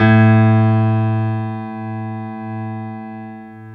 55p-pno11-A1.wav